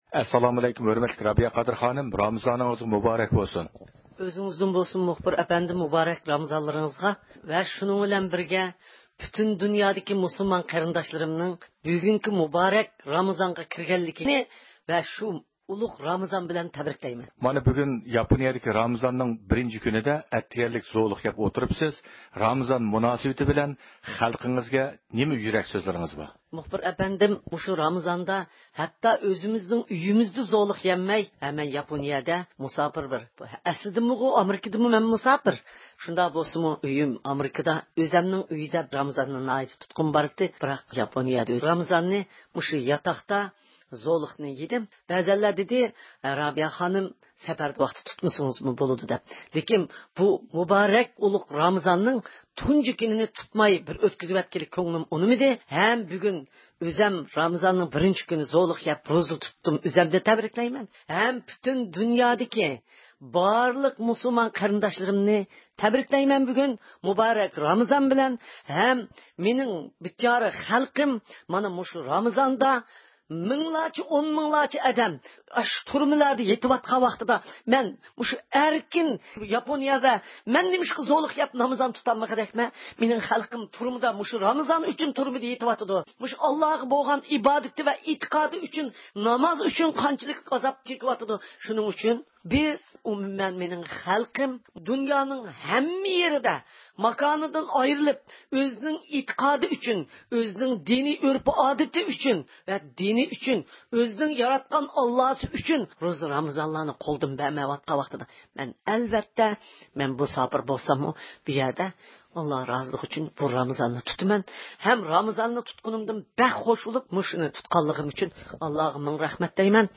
نۆۋەتتە دىققىتىڭلار، رابىيە قادىر خانىم بىلەن ئېلىپ بېرىلغان سۆھبىتىمىزدە بولغاي.